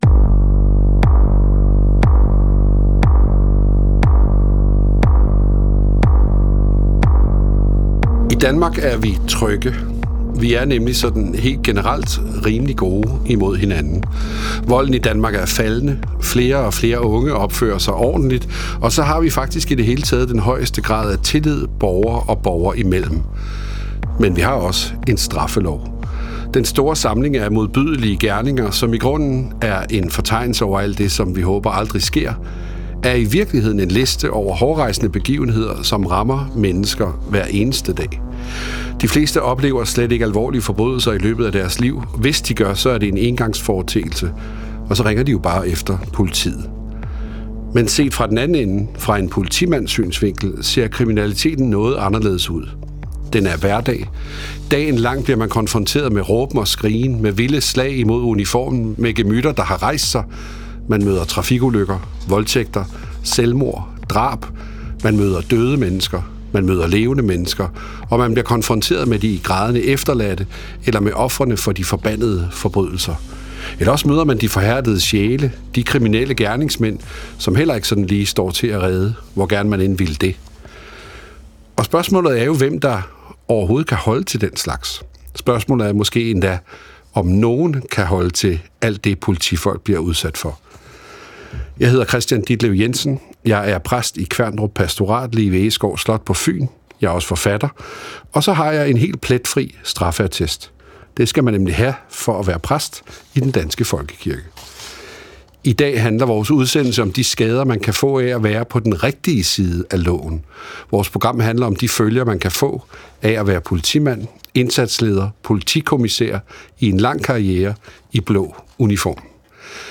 Vært: Kristian Ditlev Jensen.